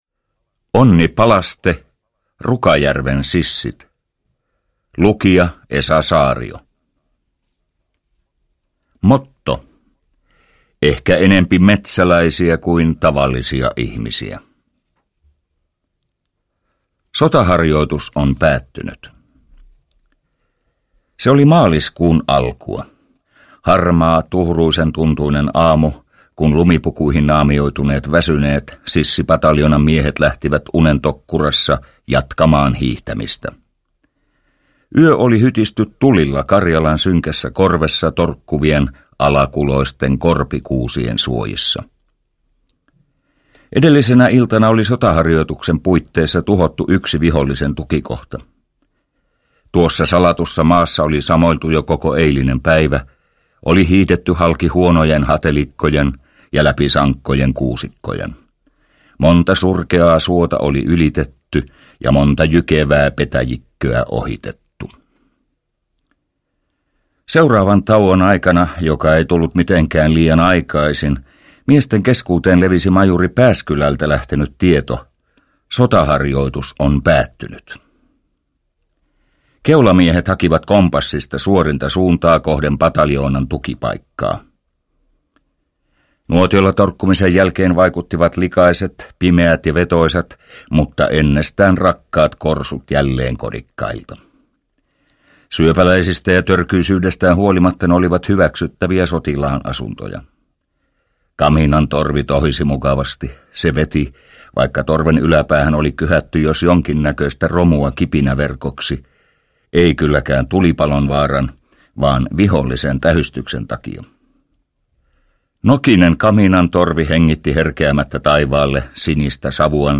Vihollistakin julmempi vastustaja oli pohjolan armoton pakkanen. Äänikirjan kertoja on näyttelijä Esa Saario.
Uppläsare: Esa Saario